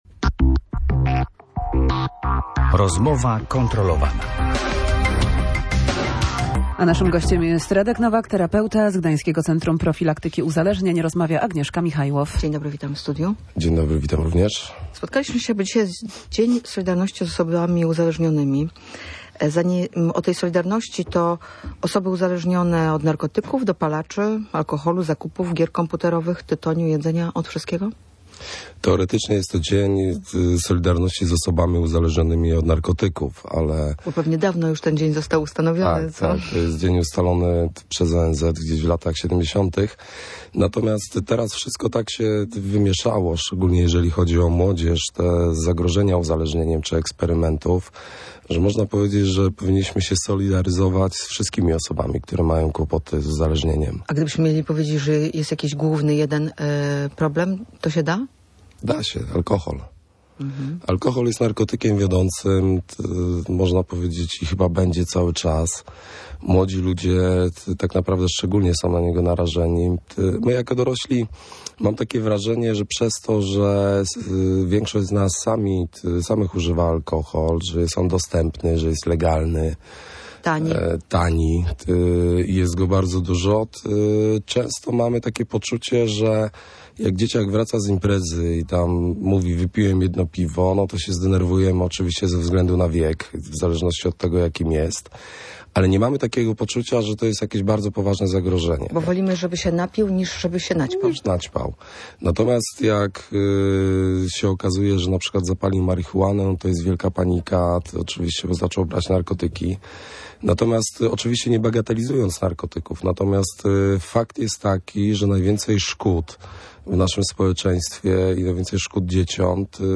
Jak tłumaczył gość Radia Gdańsk, uzależnienie wynika nie z ilości, ale z regularności jego spożywania.